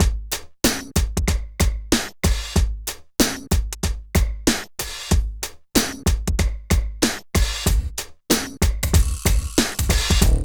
87 DRUM LP-R.wav